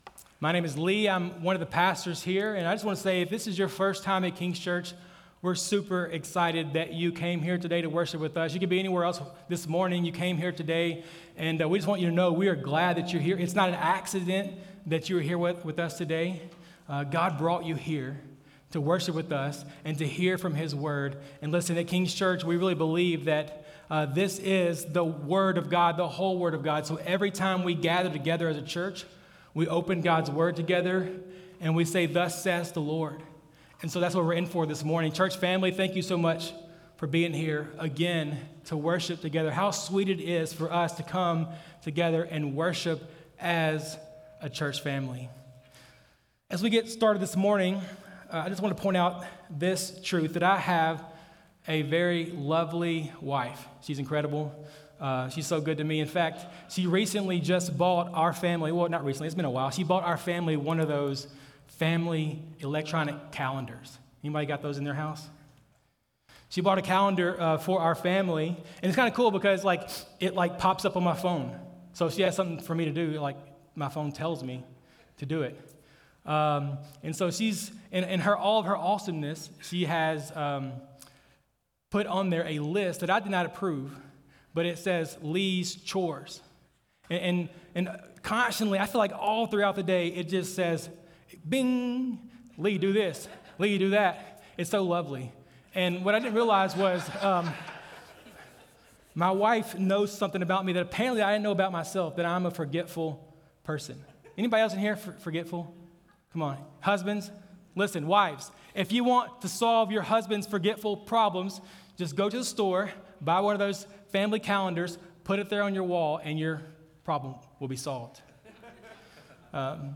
Feb22Sermon.mp3